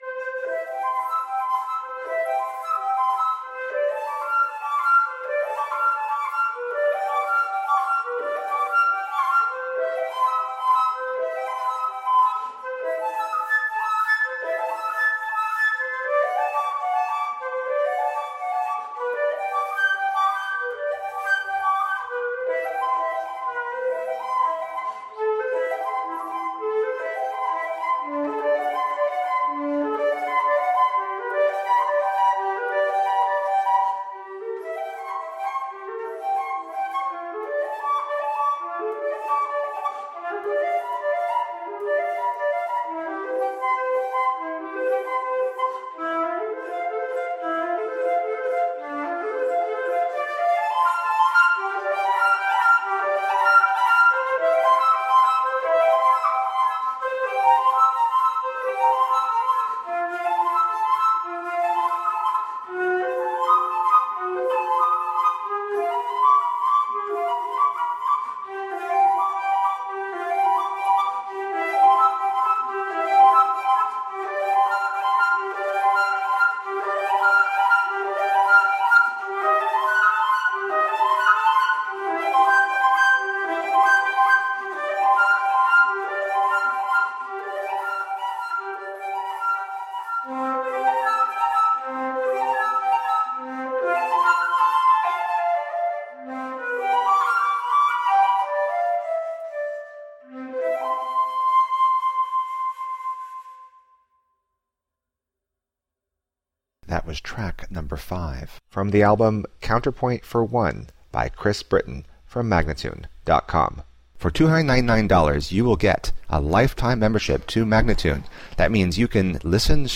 unaccompanied flute
dazzling and virtuosic transcriptions for solo flute